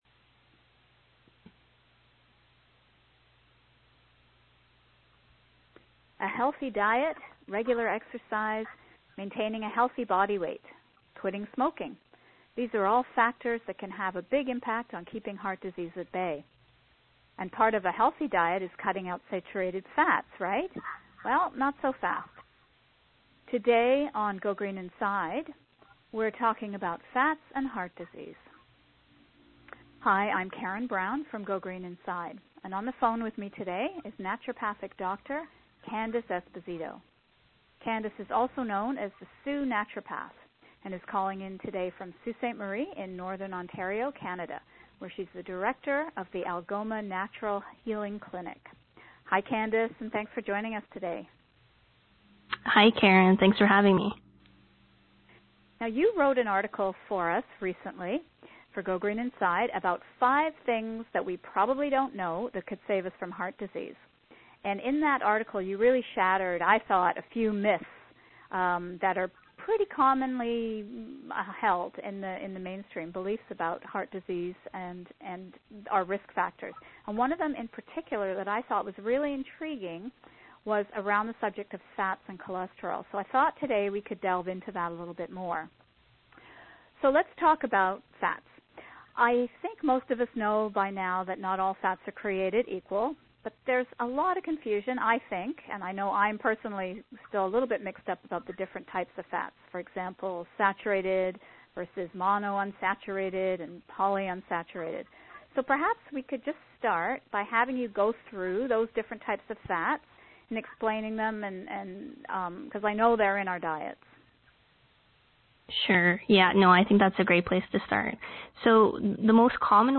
In this podcast, GoGreenInside® speaks with naturopathic doctor